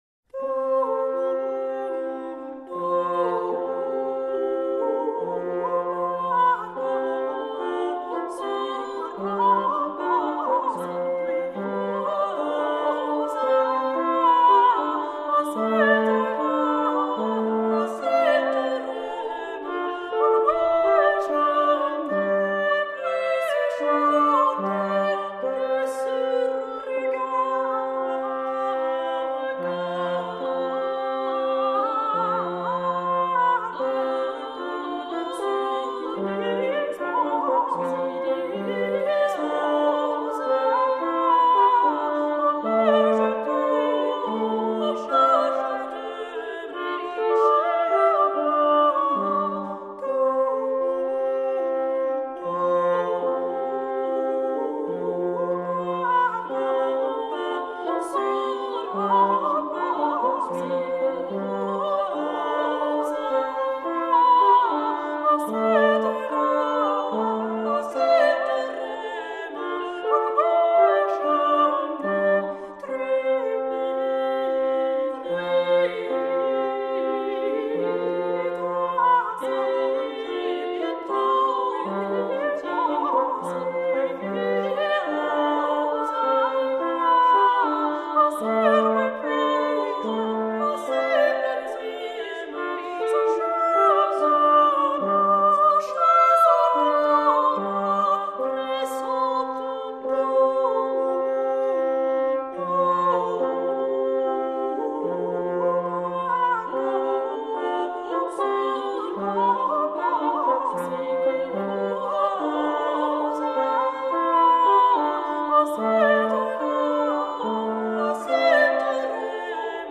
Canon